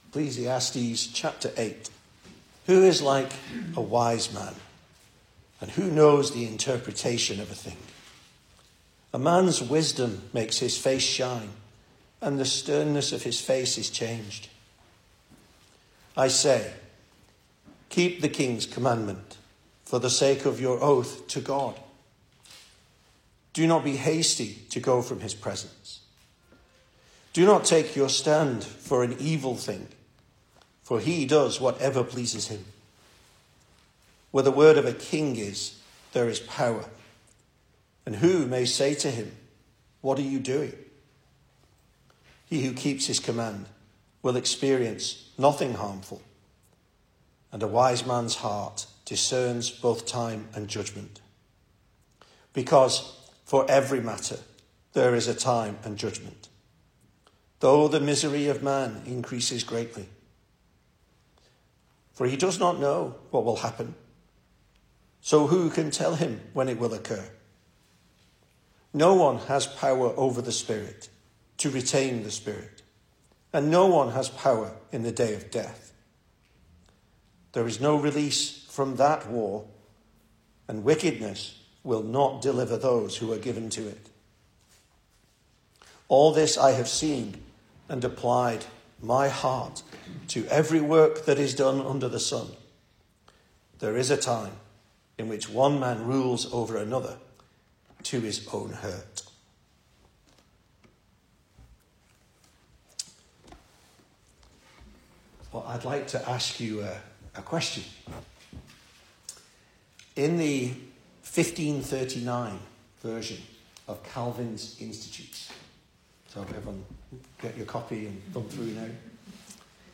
2026 Service Type: Weekday Evening Speaker